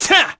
Neraxis-Vox_Attack1_kr.wav